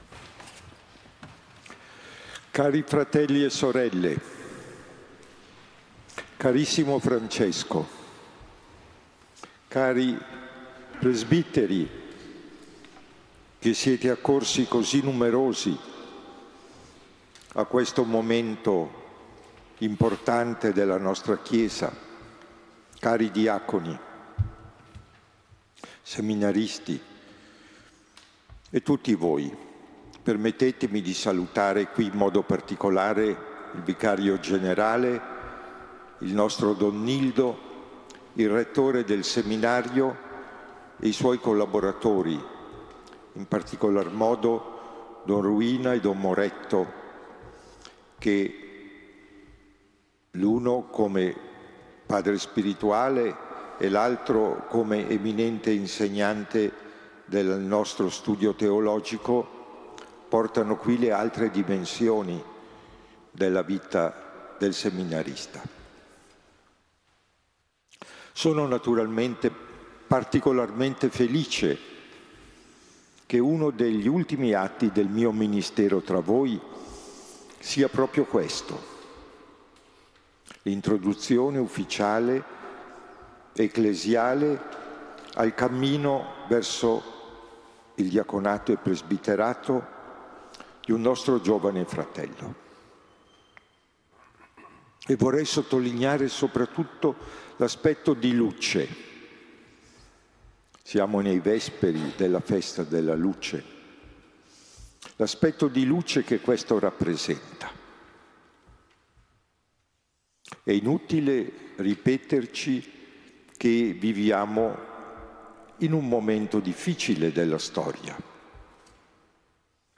Ecco l'audio dell'Omelia di mons. Massimo Camisasca.
La Messa, nella solennità del beato cardinale Andrea Carlo Ferrari, è stata presieduta dall’Amministratore apostolico della nostra diocesi monsignor Massimo Camisasca.